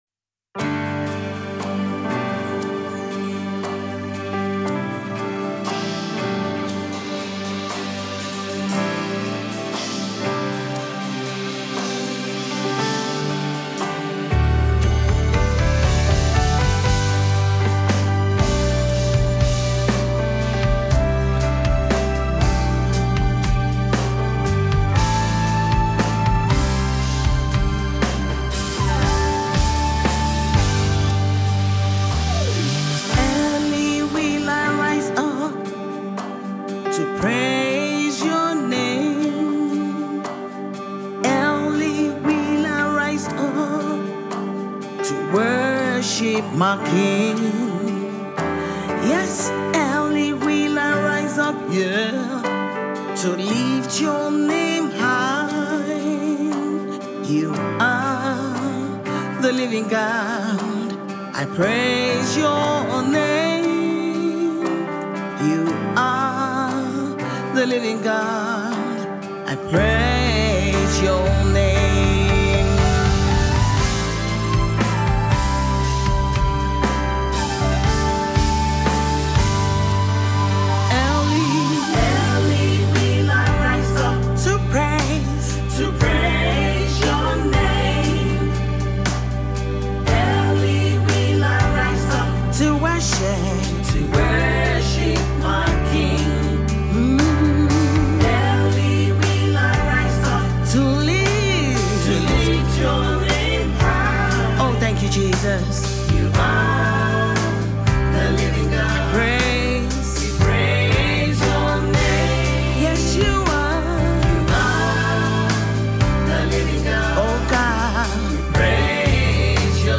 Nigerian contemporary UK-based gospel minister